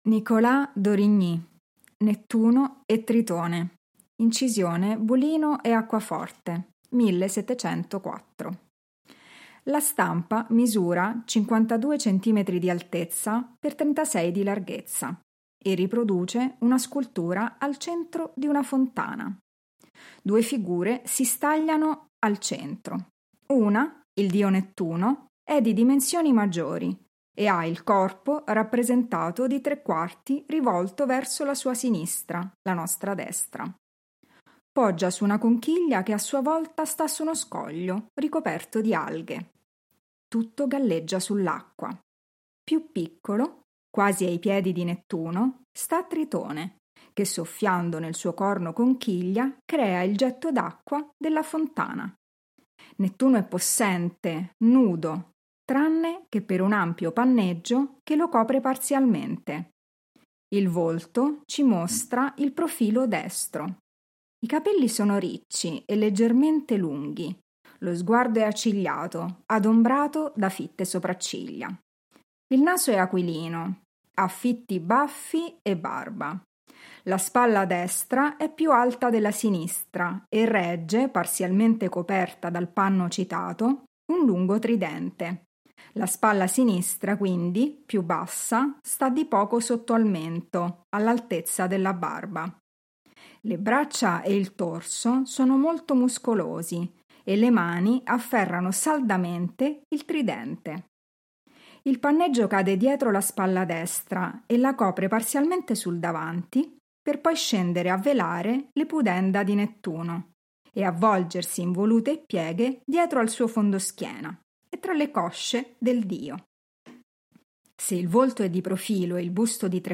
Audio-descrizioni sensoriali: